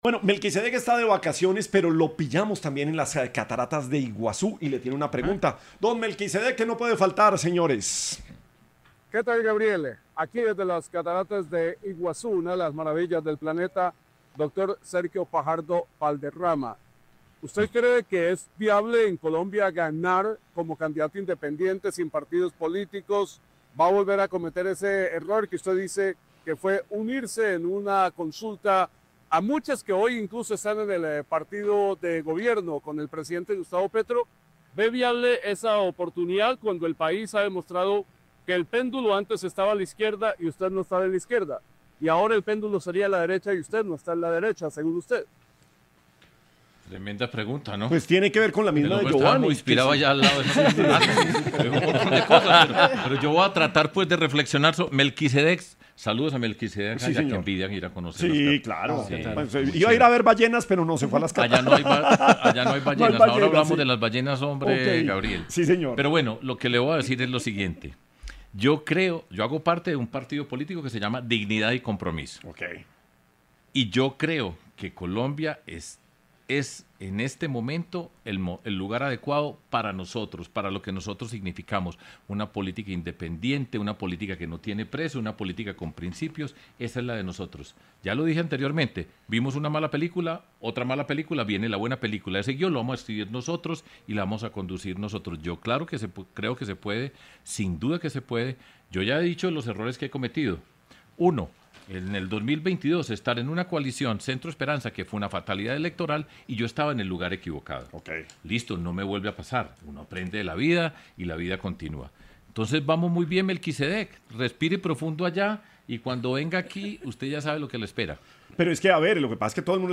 Sergio Fajardo, exgobernador de Antioquia y líder de la coalición Dignidad y Compromiso, reconoció en ‘Sin Anestesia’ de la Luciérnaga de Caracol radio que uno de los errores más grandes de su carrera política fue haberse unido en 2022 a la coalición Centro Esperanza, un acuerdo que calificó como una “fatalidad electoral”.